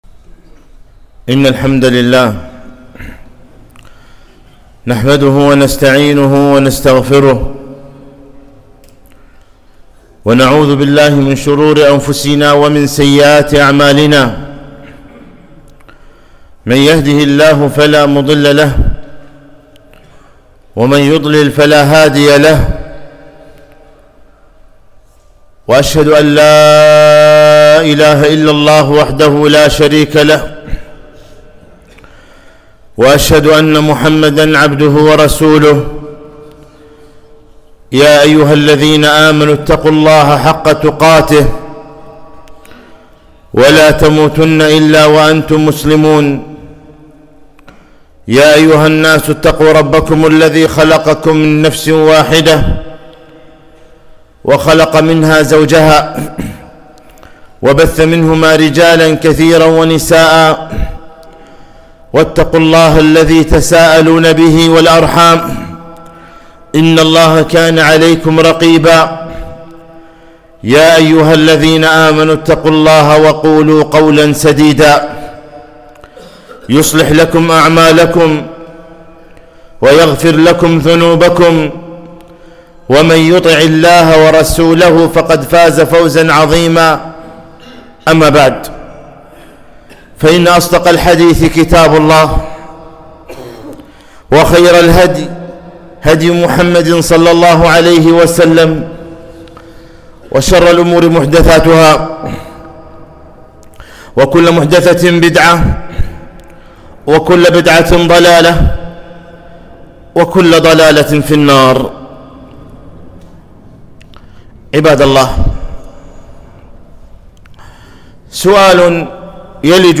خطبة - مراقبة الله في السر والعلن